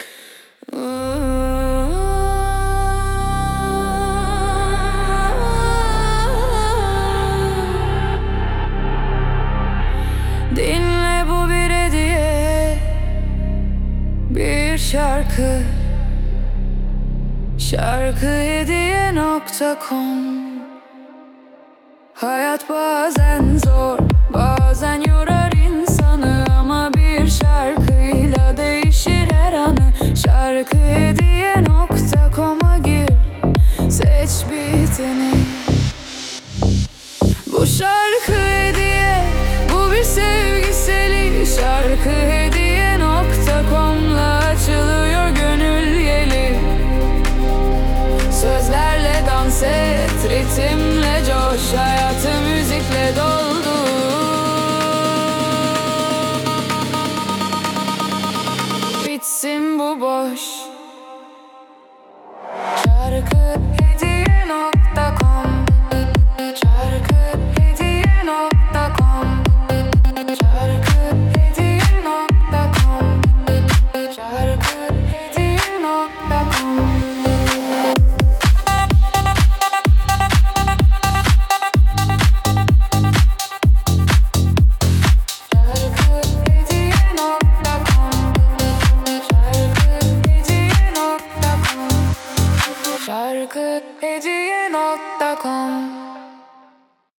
🎤 Vokalli 19.10.2025